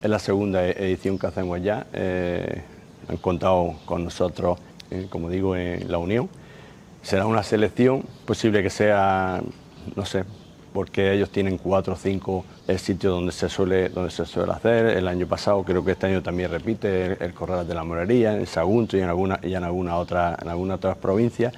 03-07_cante_minas_alcalde_lucainena.mp3